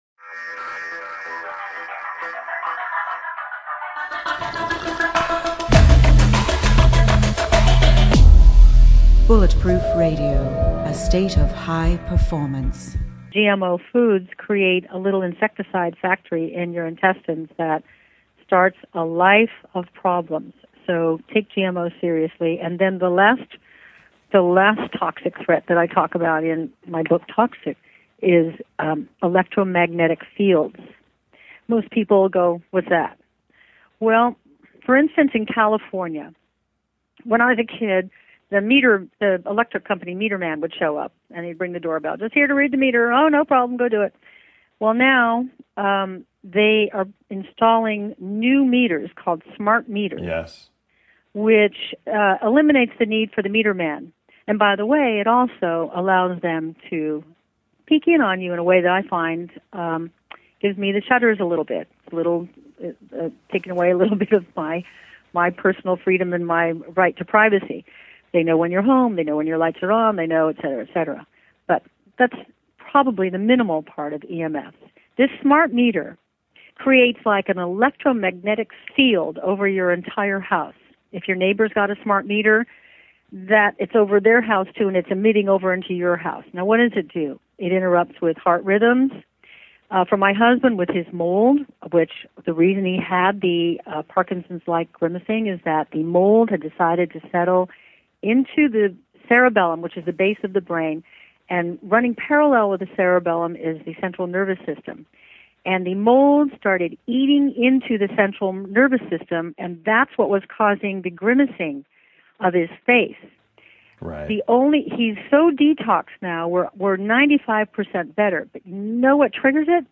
In addition to the quotes just provided from the book Tox-Sick, Somers recently gave an interview with BULLETPROOF Radio about her new book.  Here are a few quotes plus an audio excerpt from the interview: